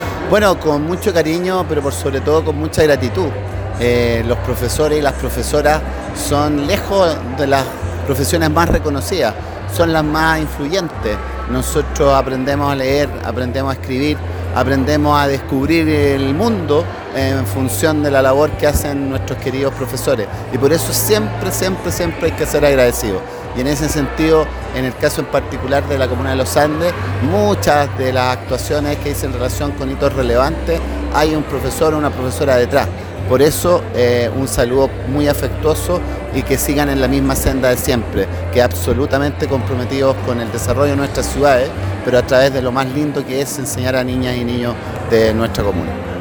Con la presencia de autoridades y junto a sus pares, los profesionales de la educación de Los Andes se dieron cita en el gimnasio del Liceo Maximiliano Salas Marchan para la ceremonia realizada en su honor, esto en el contexto del día del profesor que se celebra todos los 16 de octubre, coincidiendo con la fundación del Colegio de Profesores de Chile en 1974.
En esta oportunidad, el Alcalde de Los Andes, Manuel Rivera, se dirigió a los presentes resaltando la importante labor que cumplen los docentes “la docencia es por lejos la profesión más reconocida, es la más influyente ya que aprendemos a escribir , a leer, a descubrir el mundo en función de la labor que hacen nuestros queridos profesores, es por eso que siempre tenemos que ser agradecidos y en el caso de Los Andes, muchas de las actuaciones que dicen relación con hitos relevantes tienen a un profesor detrás”
Manuel-Rivera-Alcalde-de-Los-Andes-EDITADO-1.m4a